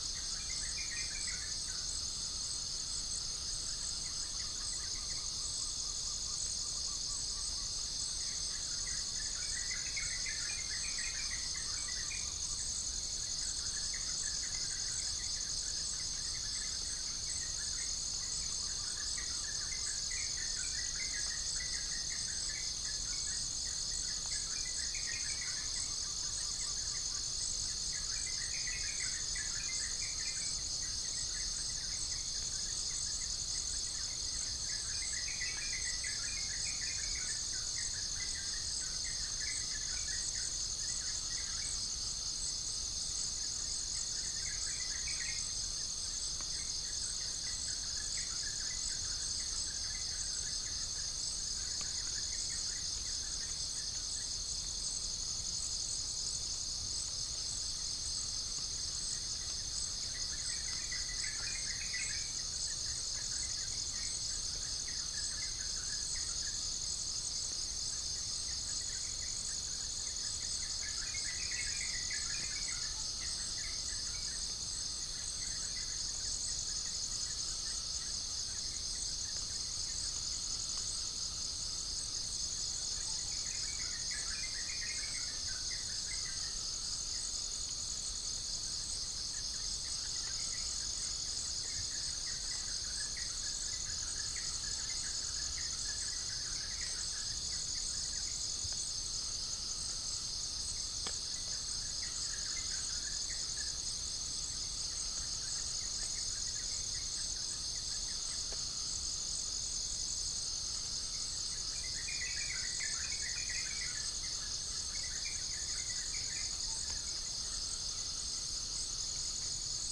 Spilopelia chinensis
Geopelia striata
Gallus gallus
unknown bird
Pycnonotus goiavier